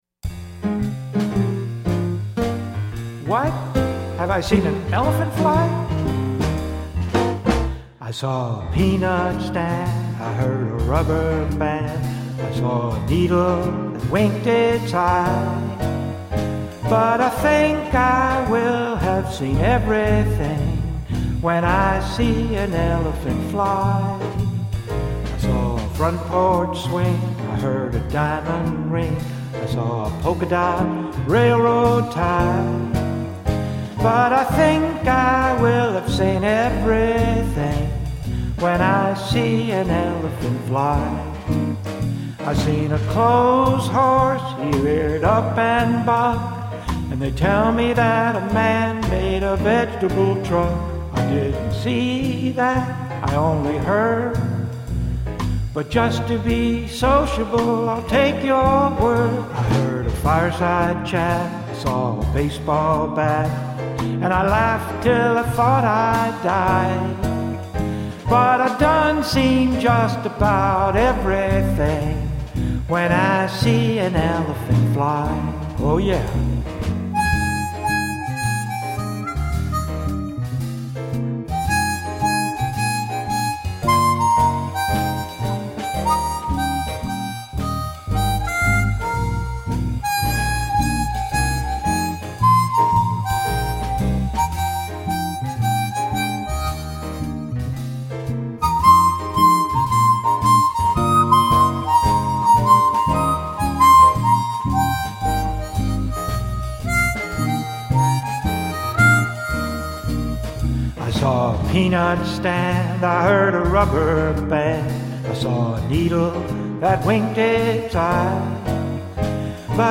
kicks-off this humorous CD of old-time animal songs.